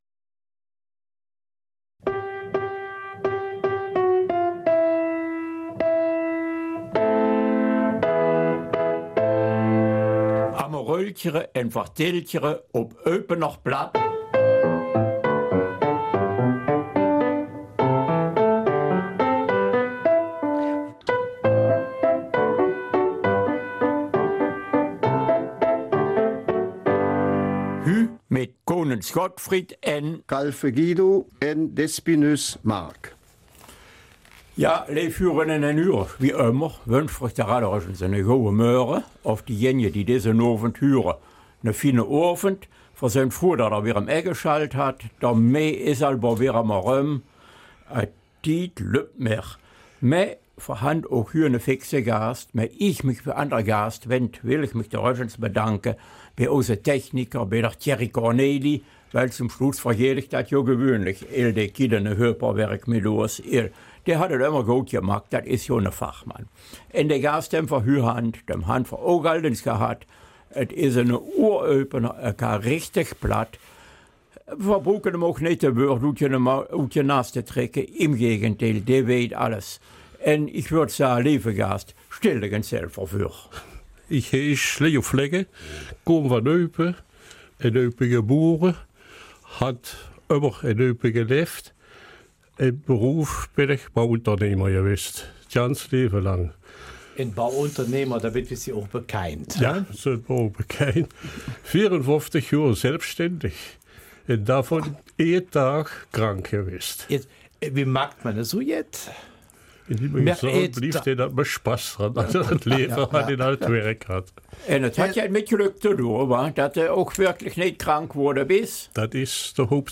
Eupener Mundart: Von traditionell zu modern
Lauschen wir der Stimme eines leidenschaftlichen Handwerkers.